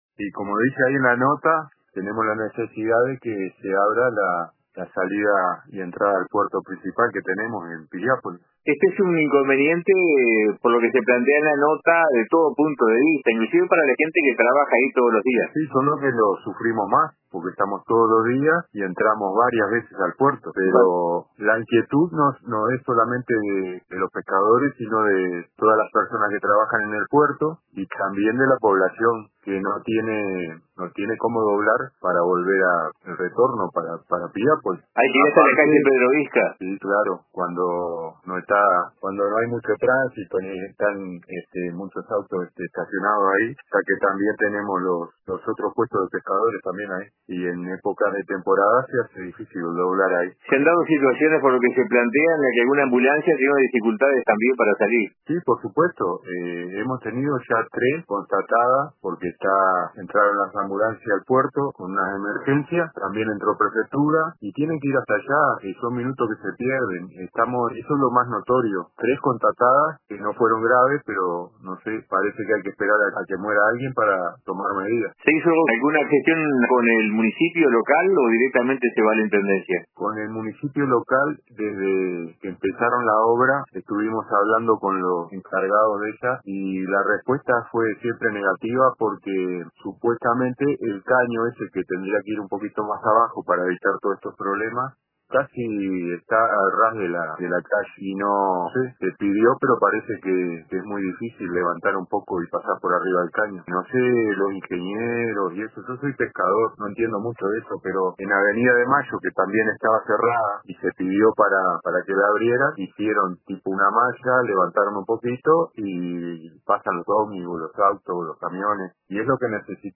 compartió en una entrevista con el informativo de RADIO RBC